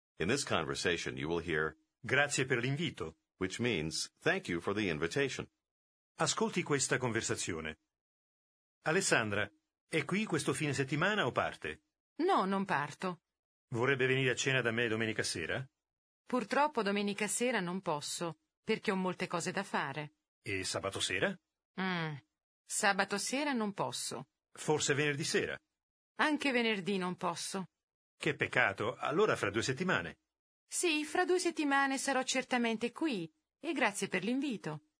Аудио курс для самостоятельного изучения итальянского языка.